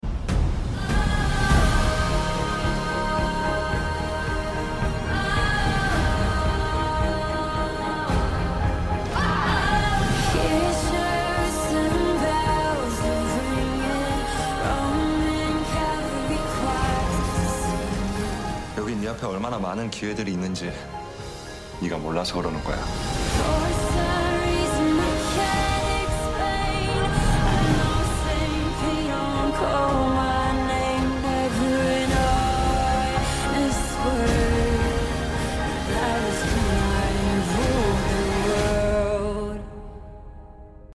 golden voice😍🔥
Angel voice🥺🤍
this is so calming IM LITERALLY FLOATING